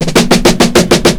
FILL 1    -R.wav